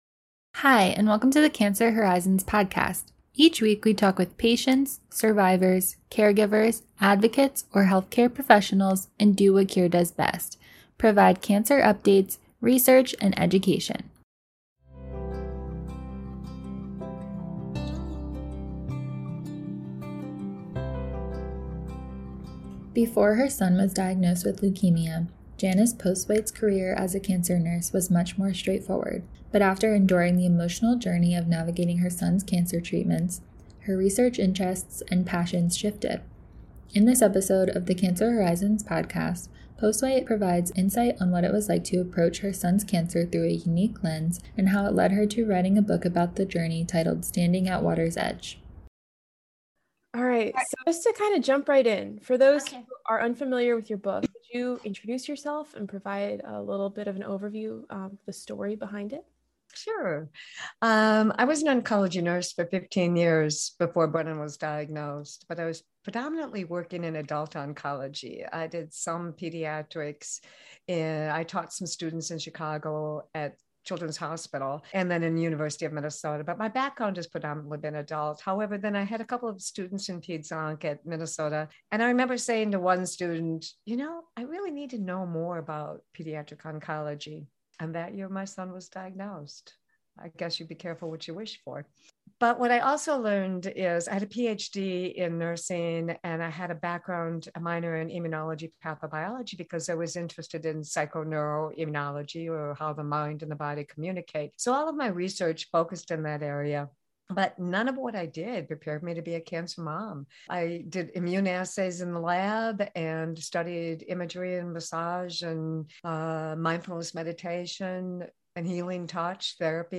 On this episode of the “Cancer Horizons” podcast, a cancer nurse explains how her son’s leukemia diagnosis transformed her work, her research and her outlook on life.